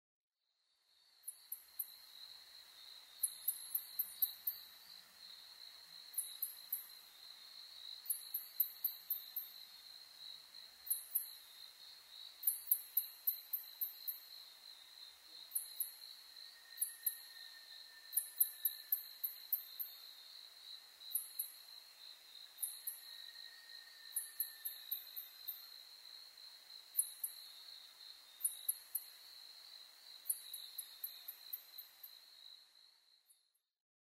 コウモリ sp.　a kind of a Bat
Mic.: Sound Professionals SP-TFB-2  Binaural Souce
他の自然音：　 ツヅレサセコオロギ・ニホンジカ